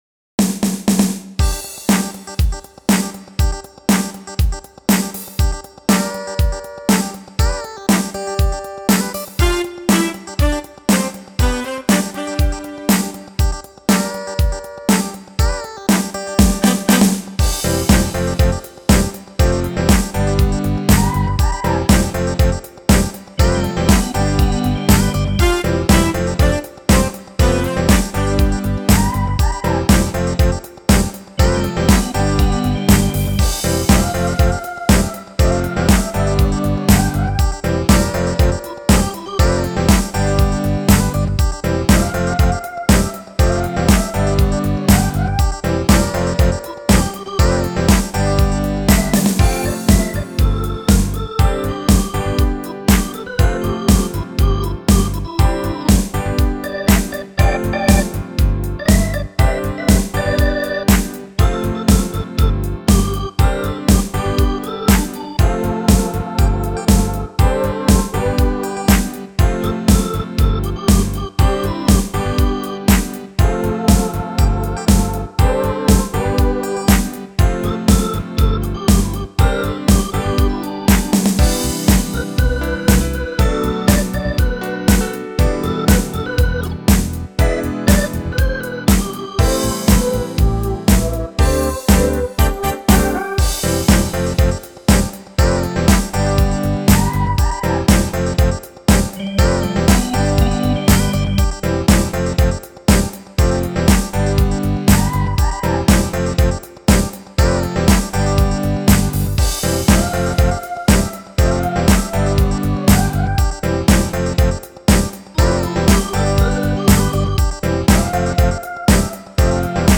thuiyc27re  Download Instrumental
This great dance tune
[Pop/Rock List]